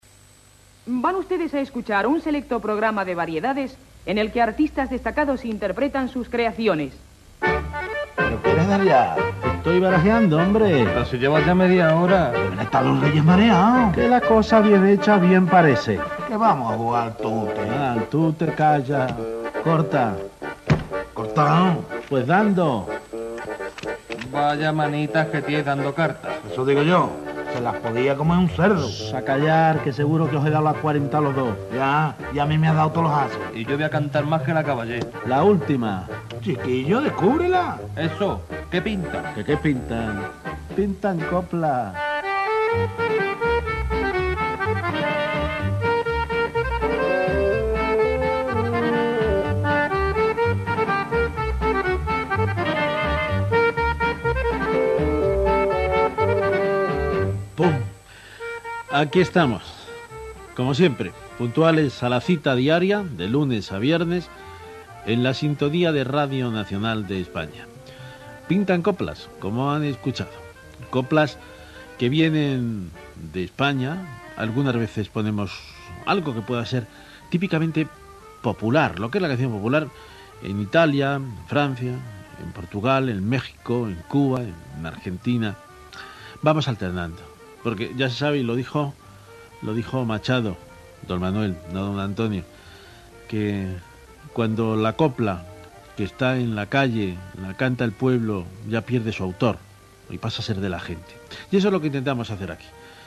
Careta del programa, presentació del programa.
Musical